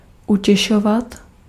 Ääntäminen
France: IPA: [ʁe.kɔ̃.fɔʁ.te]